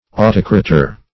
autocrator - definition of autocrator - synonyms, pronunciation, spelling from Free Dictionary Search Result for " autocrator" : The Collaborative International Dictionary of English v.0.48: Autocrator \Au*toc"ra*tor\, n. [Gr.